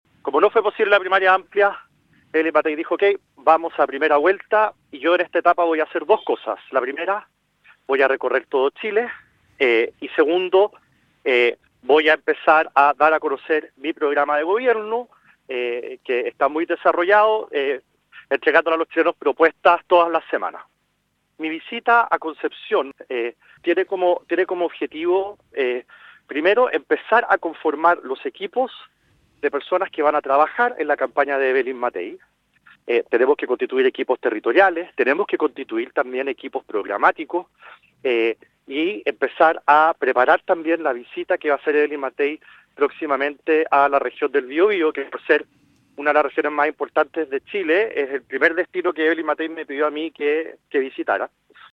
Previo al encuentro, el presidente de la UDI, diputado Guillermo Ramírez, explicó que como no habrá primarias de oposición, Matthei decidió recorrer el país presentando su programa de Gobierno.